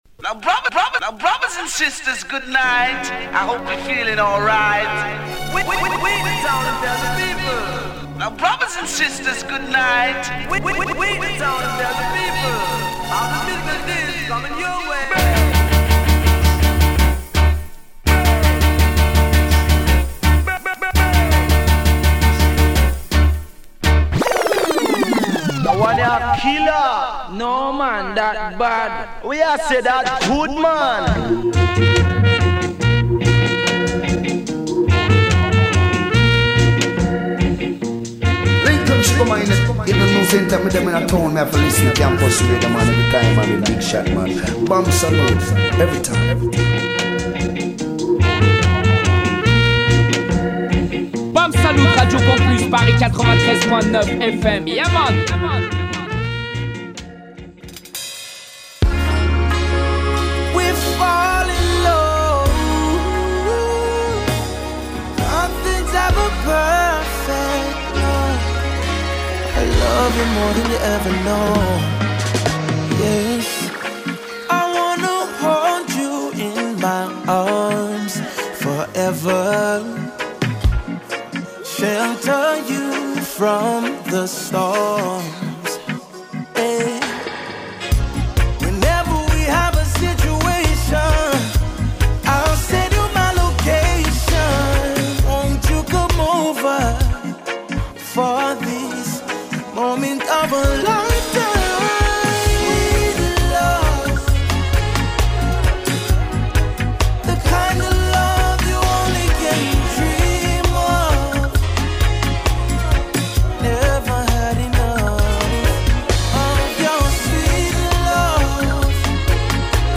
Type Musicale